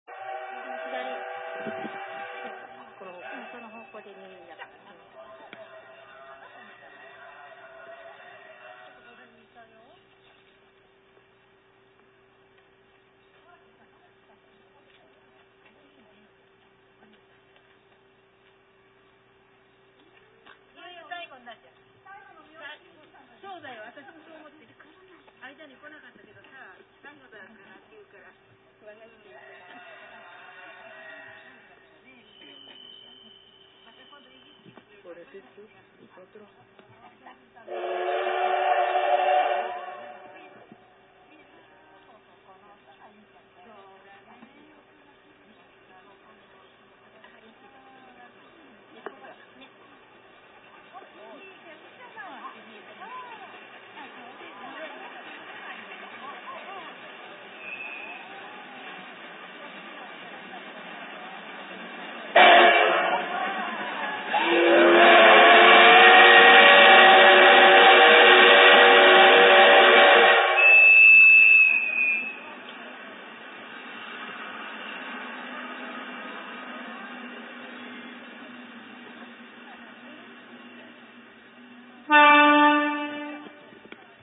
ｄ５１機関車の汽笛です
やっと、駐車場らしき、少し広い所にでたので、ここで録音をすることにしました。遠くから汽笛も聞こえて、準備万端と思ったのですが、ＳＬが近づくにつれ、近所のおばさまたちが集まって来て、今度は噂話に夢中で、どうにもなりませんが、